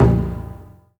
A#3 BUZZH0AR.wav